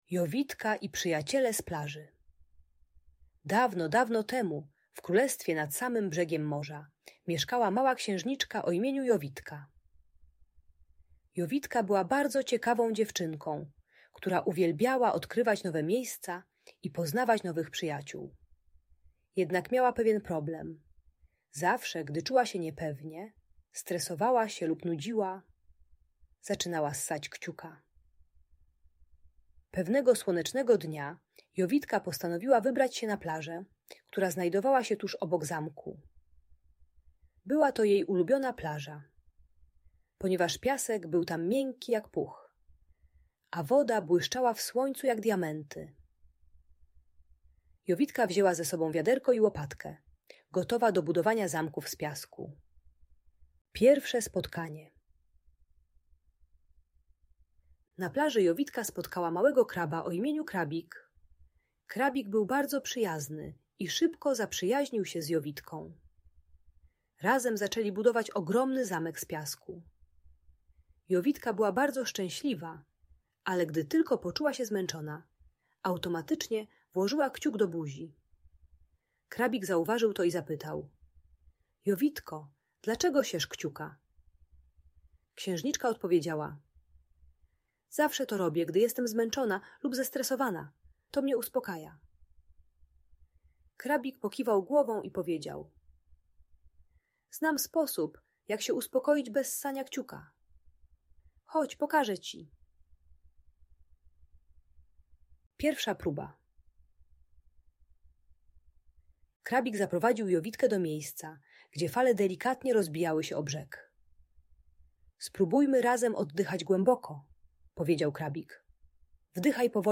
Opowieść o Jowitce i Przyjaciołach z Plaży - Audiobajka